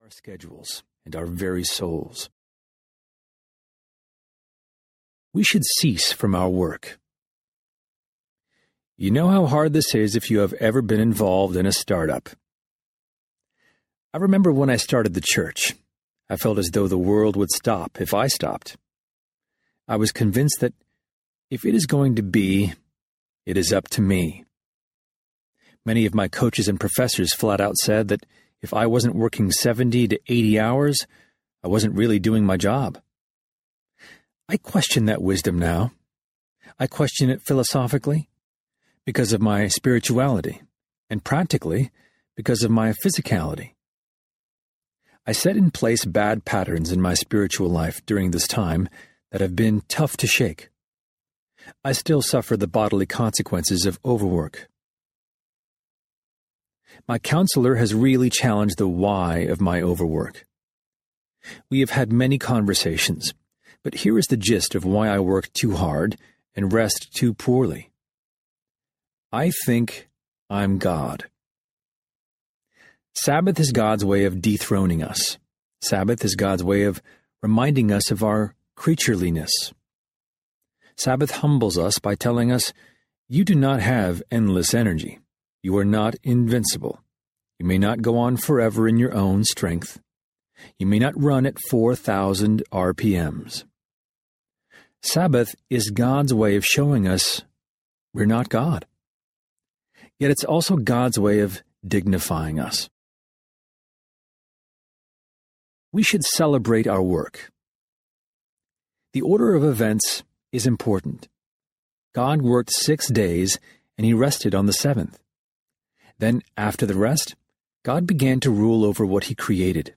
Narrator